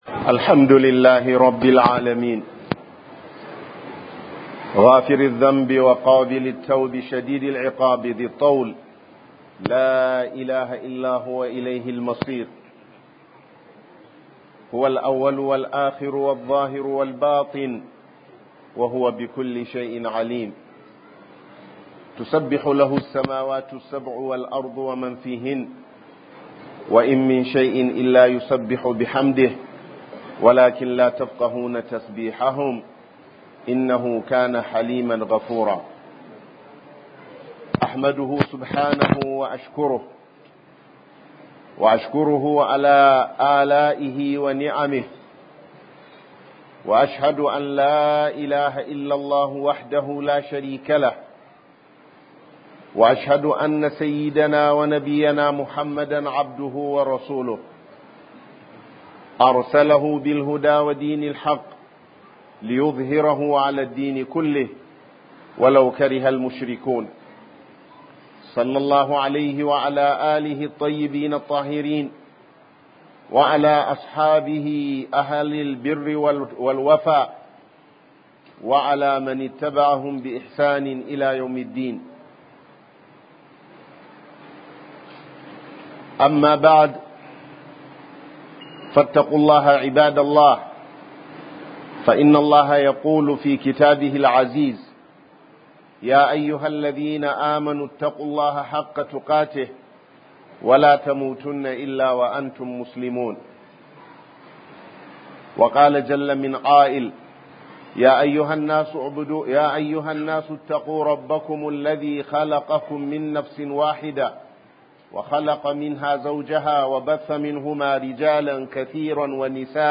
Falalar Gina Masallaci - HUDUBA